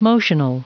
Prononciation du mot motional en anglais (fichier audio)
Prononciation du mot : motional